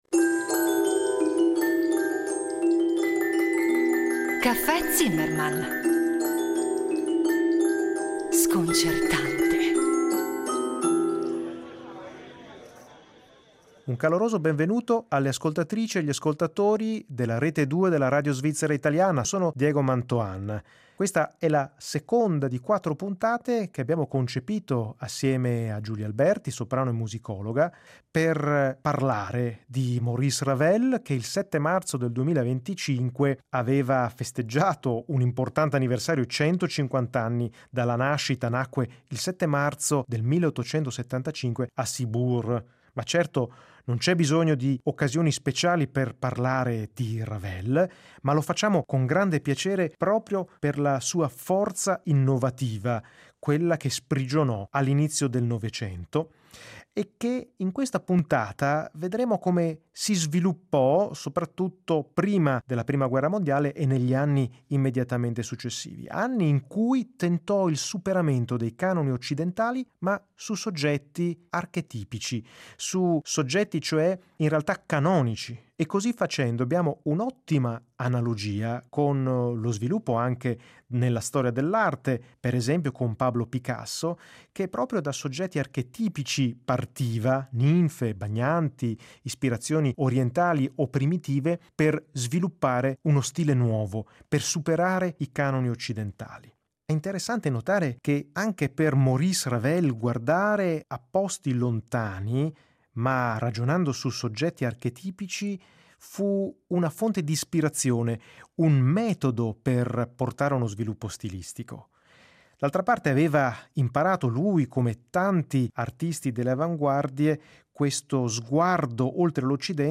E poi la musica, ovviamente, dal primitivismo più autentico che parte da suggestioni extroccidentali per immergere l’ascoltatore in un’atmosfera distante, in un nuovo mondo sonoro, fino alle contaminazioni blues.